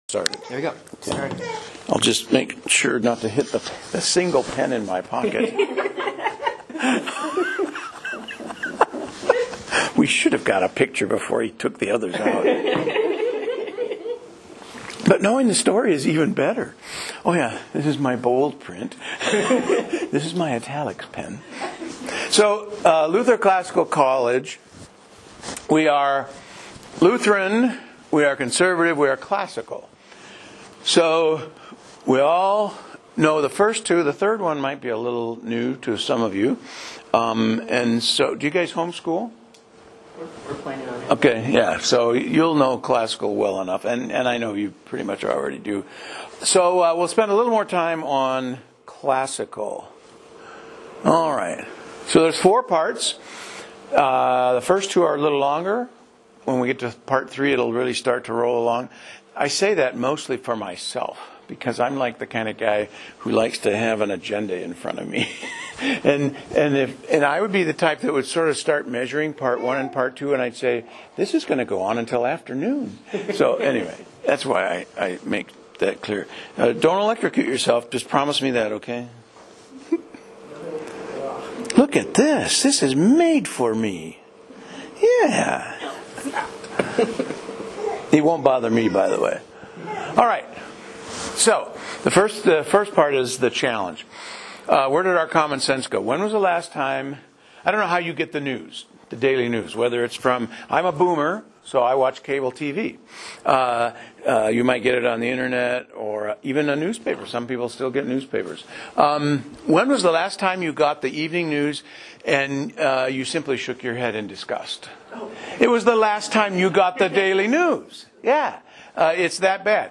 A Presentation on LCC for Adult Sunday School